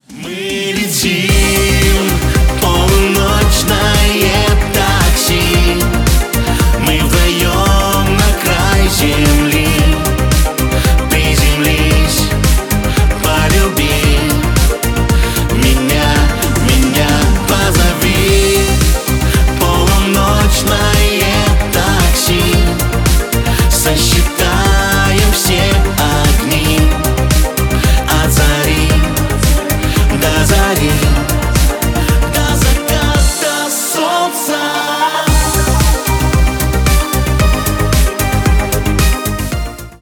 Поп Музыка
кавер # клубные